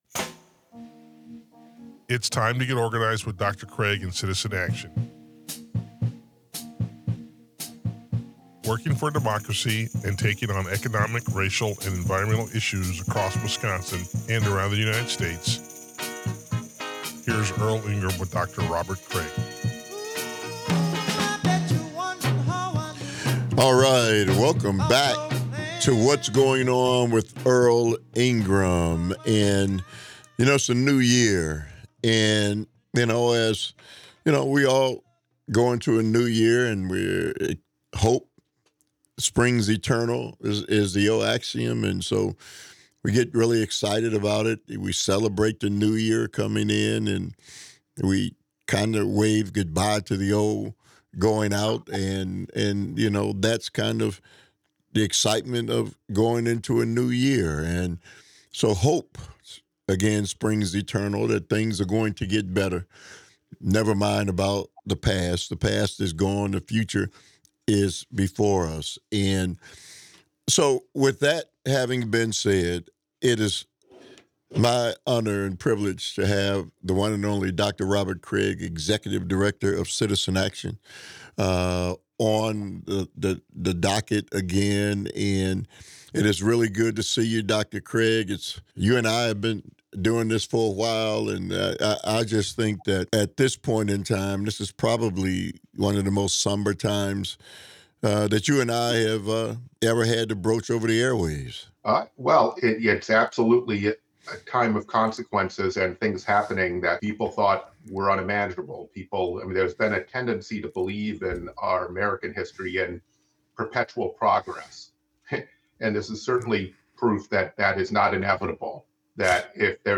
for a wide-ranging conversation on democracy and the current state of the American economy. Together, they examine cuts to education, a minimum wage that fails to meet the real cost of living, and the ongoing crisis of underfunded schools.